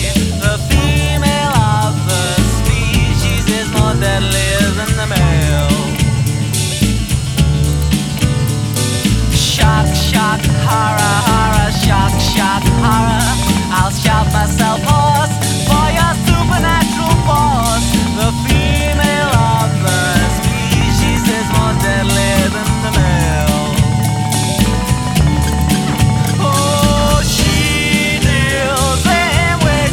• Pop
English rock band